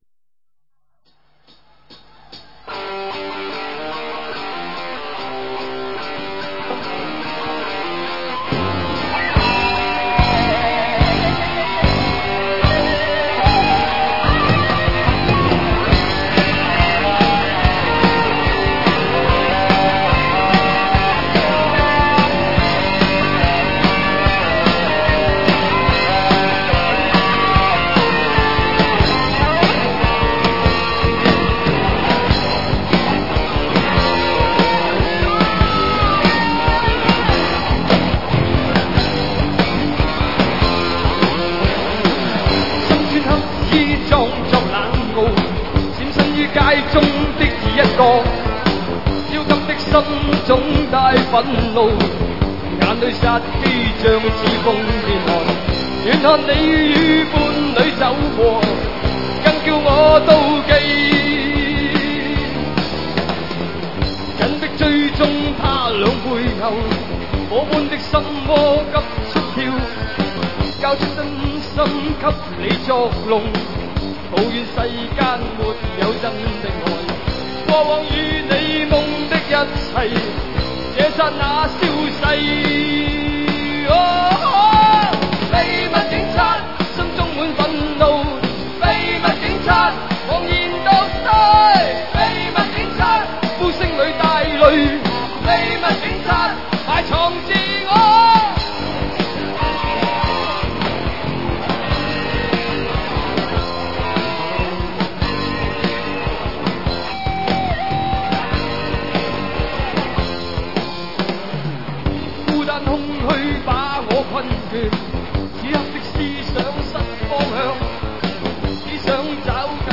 1988年【北京演唱会】